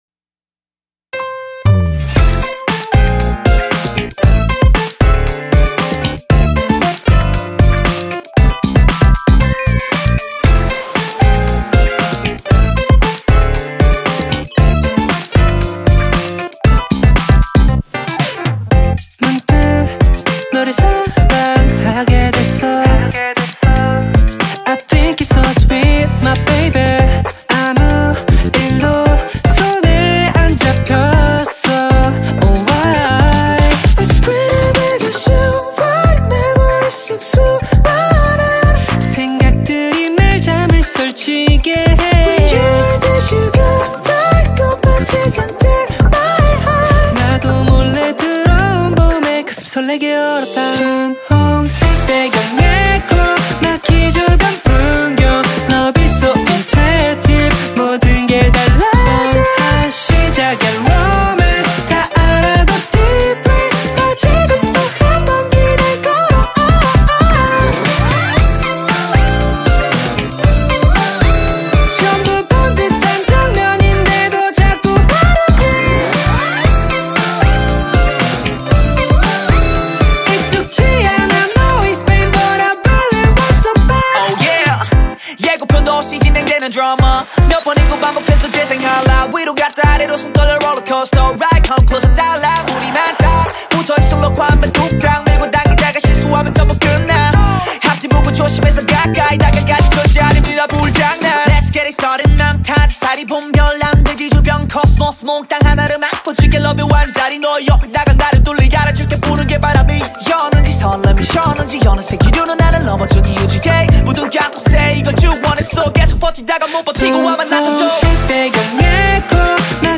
Новая корейская музыка — K-Pop